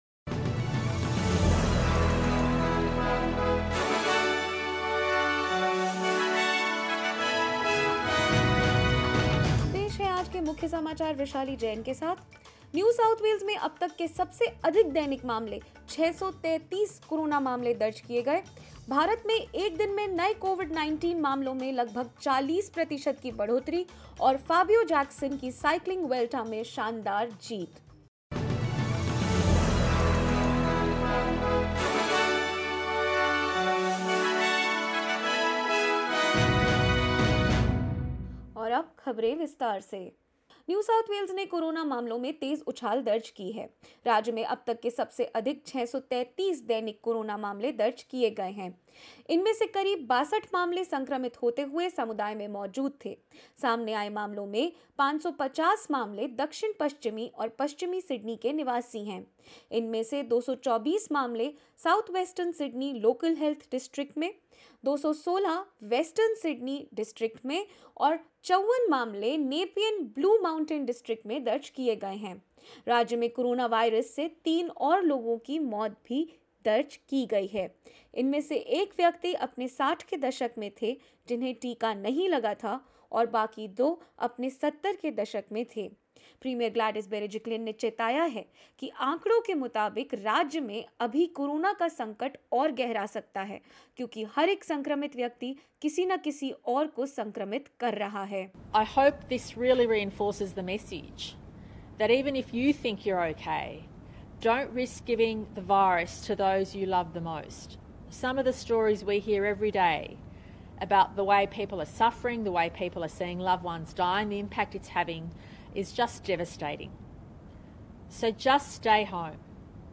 In this SBS Hindi news bulletin: NSW records its highest number of daily COVID-19 cases with 633 infections, three deaths; Queensland set to relax state-wide restrictions from Friday, 20 August; India records a 40 per cent rise in daily COVID-19 cases and more.